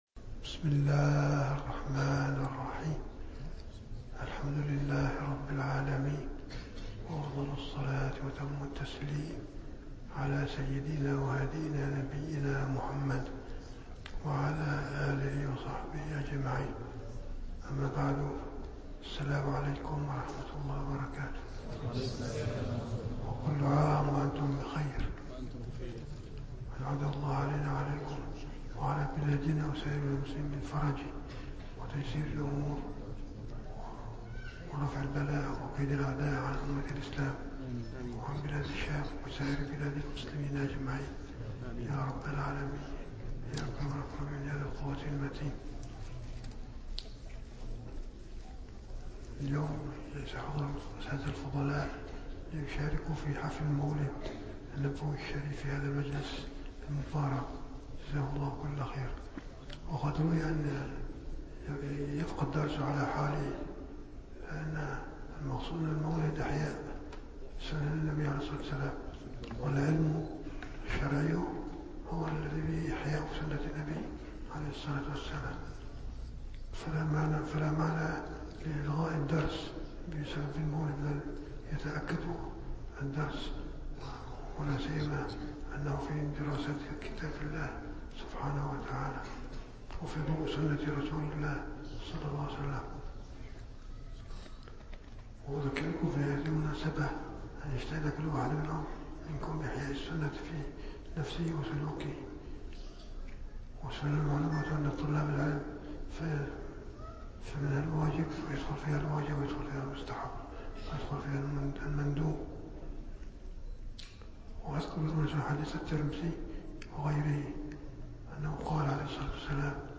- الدروس العلمية - دورة مختصرة في علوم القرآن الكريم - 11- علوم القرآن الكريم